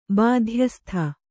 ♪ bādhyastha